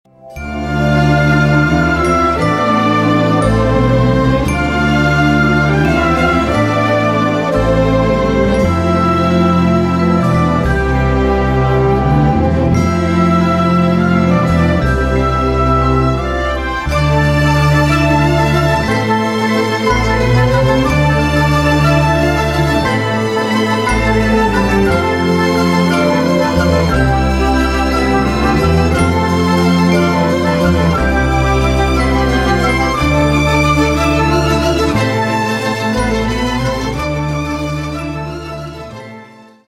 красивые
спокойные
инструментальные
оркестр
вальс
Neoclassical